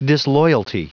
Prononciation du mot disloyalty en anglais (fichier audio)
Prononciation du mot : disloyalty